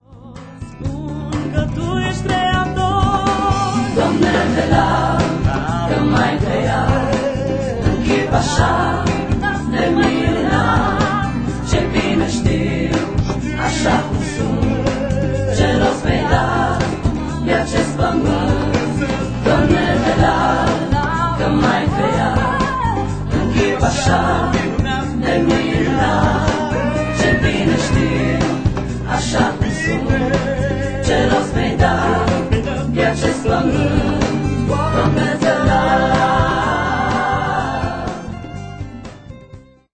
Este un adevarat compendiu de lauda si inchinare.